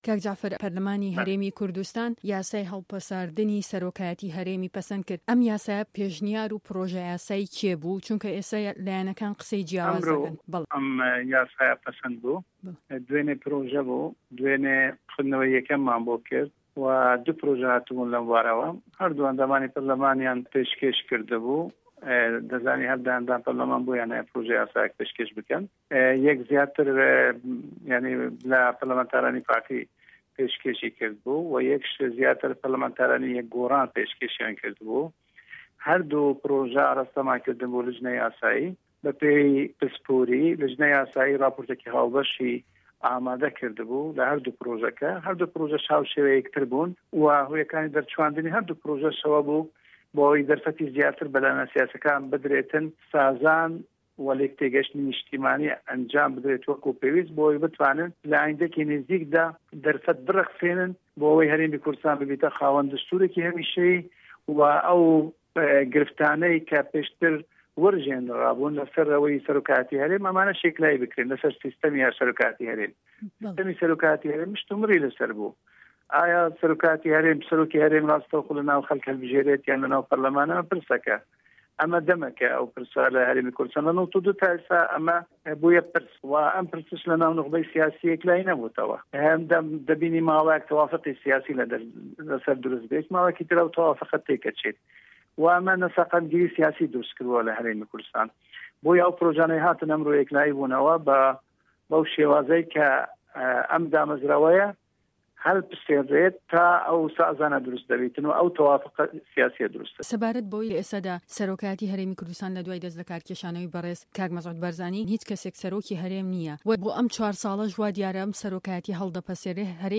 ده‌قی وتوێژه‌كه‌ی